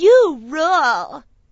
you_rule.wav